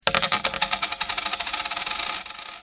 Звук упавшей монеты
Отличного качества, без посторонних шумов.
739_moneta.mp3